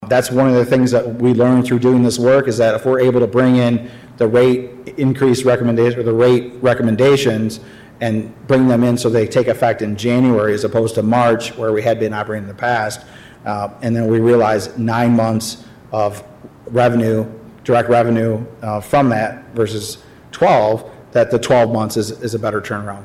KALAMAZOO, MI (WKZO AM/FM) — Kalamazoo city commissioners held a special session Monday afternoon, August 29, to discuss increases in future water utility rates.